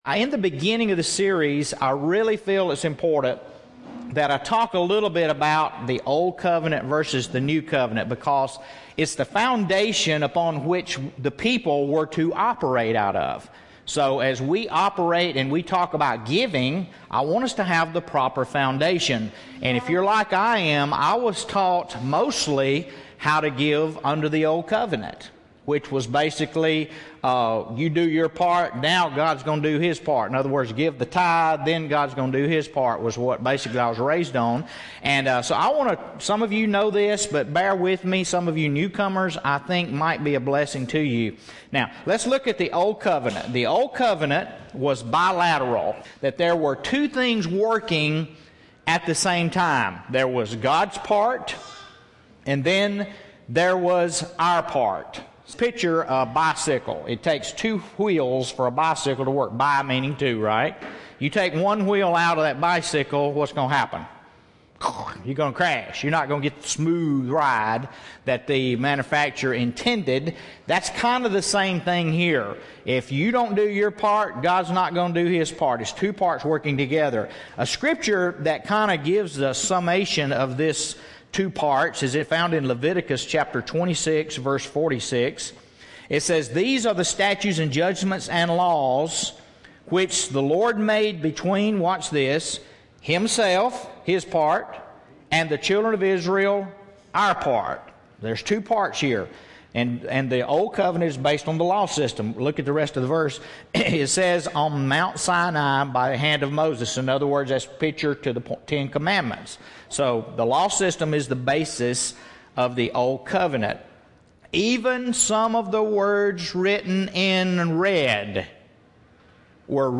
at Grace Cafe Church